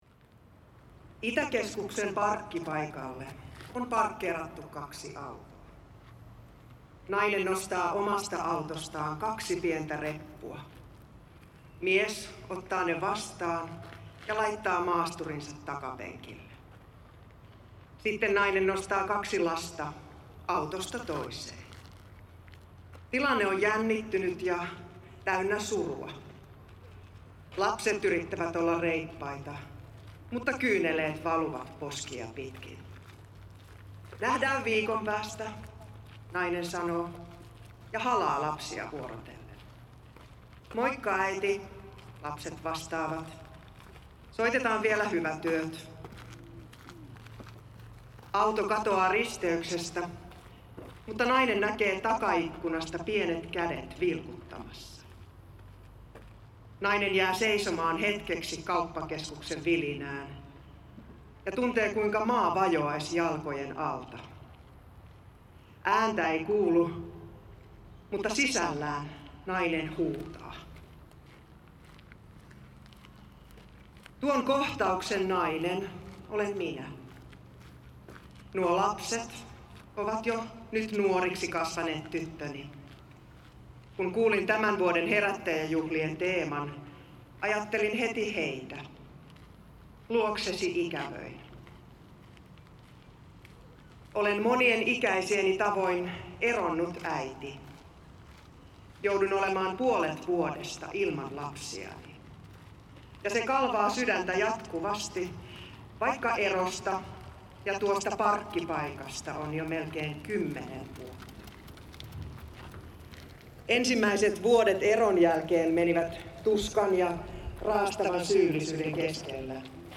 Finnish actor Laura Malmivaara gives a speech about separation and family at Herättäjäjuhlat festival in Tampere. After the speech the audience sings a hymn of Zion. The festival is the annual gathering of the religious Awakening movement.
• Soundscape
• speech